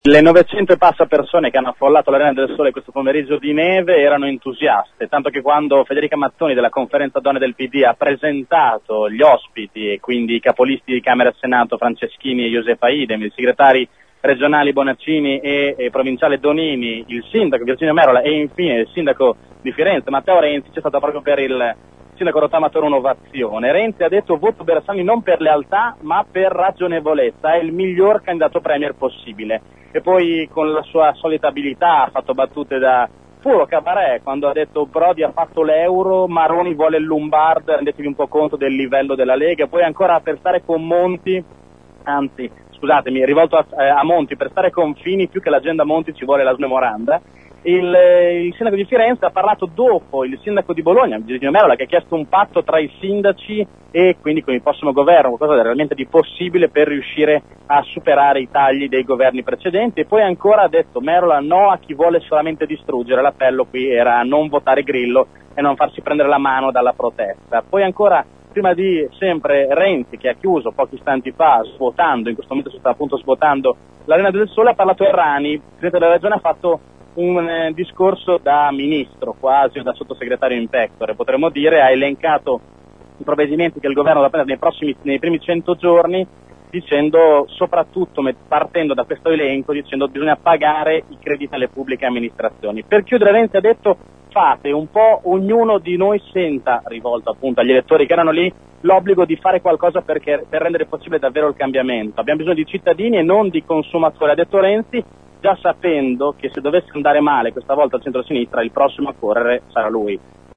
e questo è il servizio dell’inviato